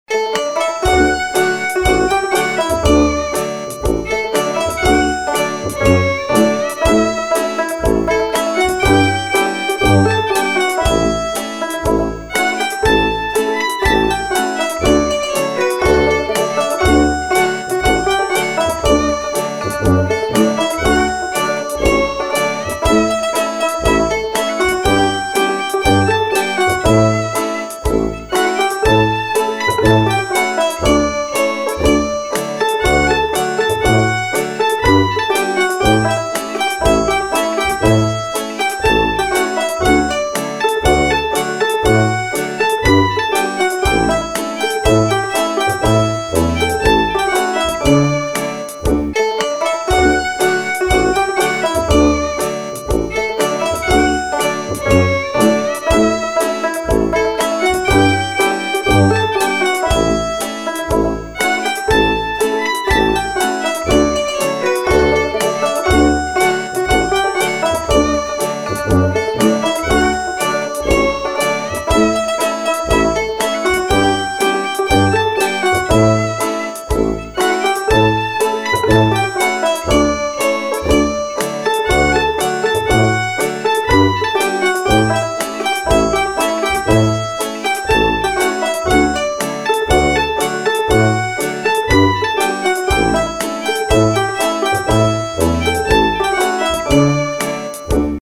C:trad French tune from the Auvergne
R:March
M:4/4
K:D